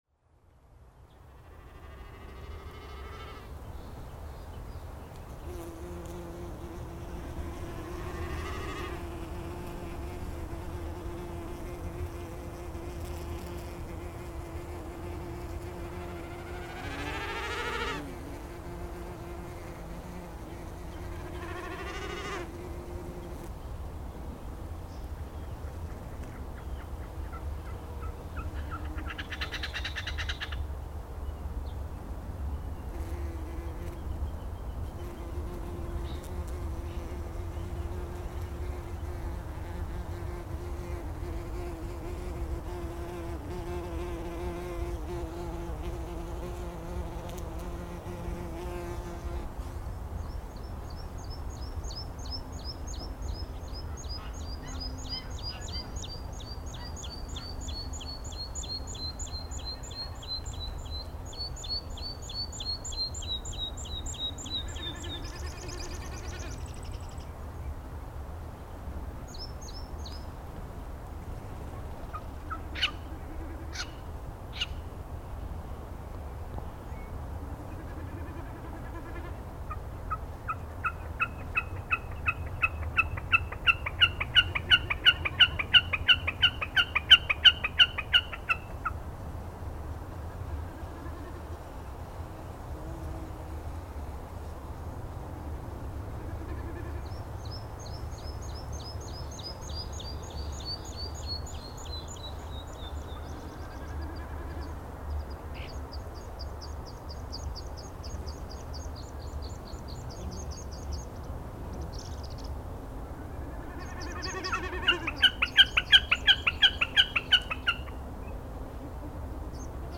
Ég kom mér fyrir við ósa Hólmsár þar sem hún rennur í Elliðavatn . Eitthvað dauft var yfir svæðinu þó auðvitað mætti heyra í fuglum í mikilli fjarlægð. Í upphafi var hávaði frá bílaumferð og flugvélum lítill, en jókst þegar nær dró hádegi.
Í fyrsta sinn nota ég Rode NT1-A hljóðnema sem ég hef ekki notað áður.
Í upptöku var skorið af við 180Hz. Ekki dugði það þó til að þagga niður í drunum frá bíla- og flugumferð höfuðborgarsvæðisins.
Má því búast við að eitthvað af þessu mikla grunnsuði komi frá blásandi borholum á heiðinni. Í gegnum djöfulgang frá mannheimum má heyra í auðnutitlingi, spóa, óðinshana, kríu, hrossagauk, lóu, þresti og gæs.